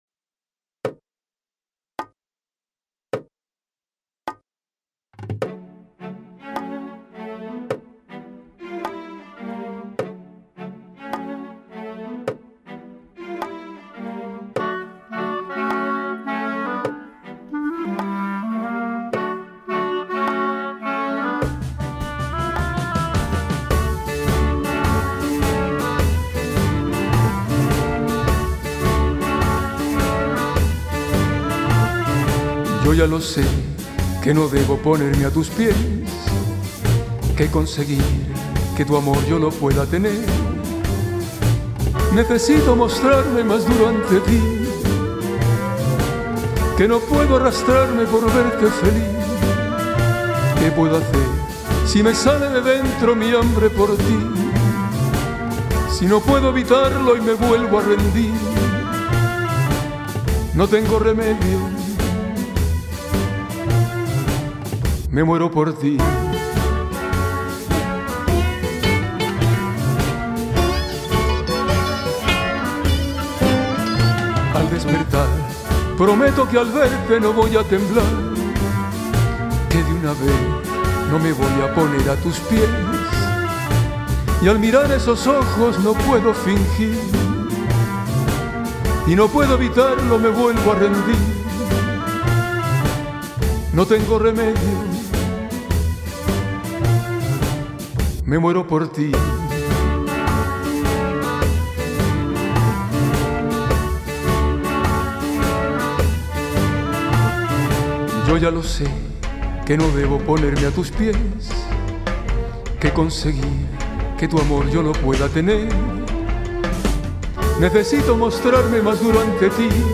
es un swing, suave